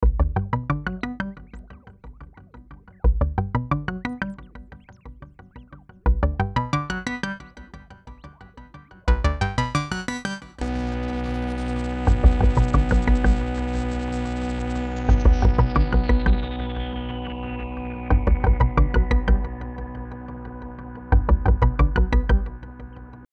Покрутил новый Xils PolyM, вот отренедрил хороший звук, попадаются очень неплохие, Но к сожалению чесно говоря показалась цена слегка завышена для такого функционала (Xils4 например поинтереснее).